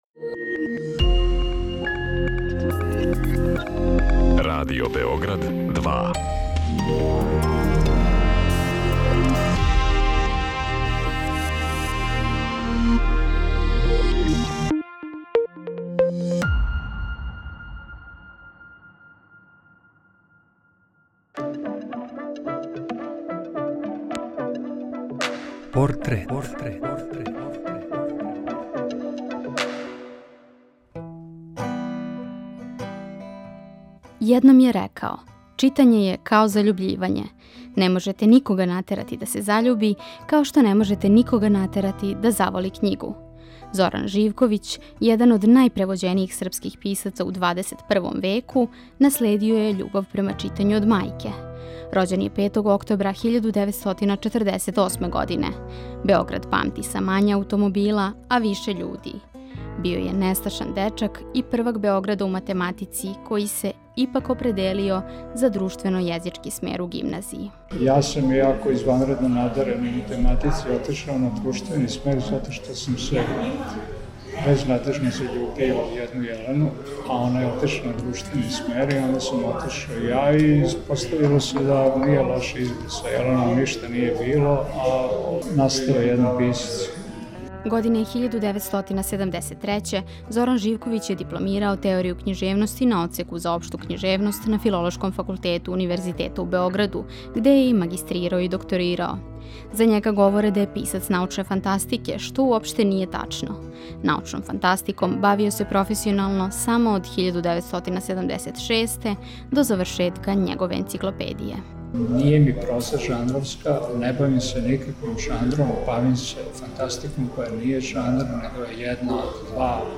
Приче о ствараоцима, њиховим животима и делима испричане у новом креативном концепту, суптилним радиофонским ткањем сачињеним од: интервјуа, изјава, анкета и документраног материјала. О портретисаним личностима говоре њихови пријатељи, најближи сарадници, истомишљеници…